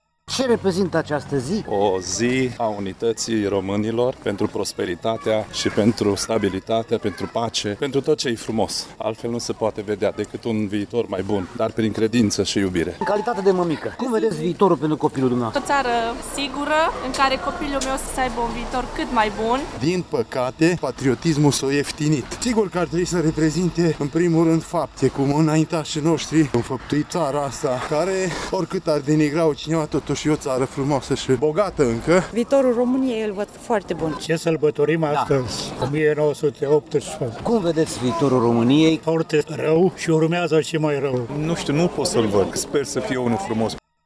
În acest context de sărbătoare, opiniile târgumureșenilor variază de la un pesimism sumbru la un optimism plin de speranță: